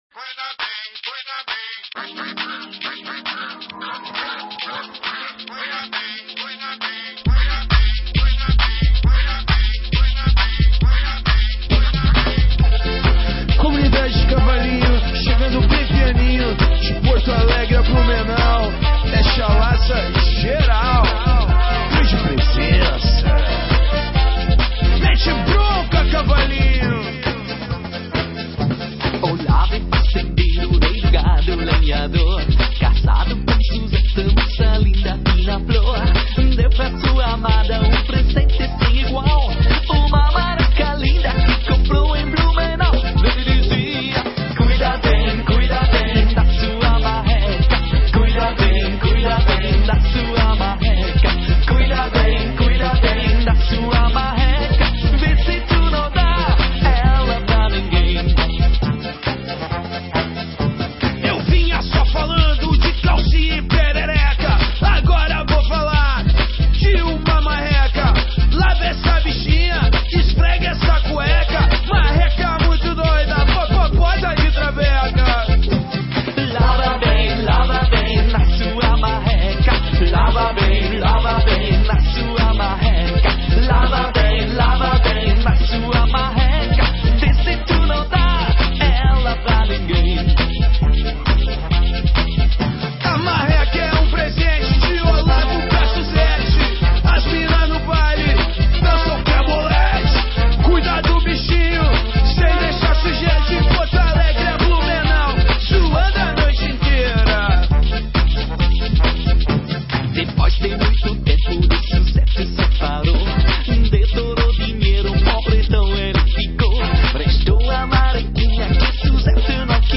grupo gaúcho conhecido por misturar rock e funk
emprestou um ar de batidão à música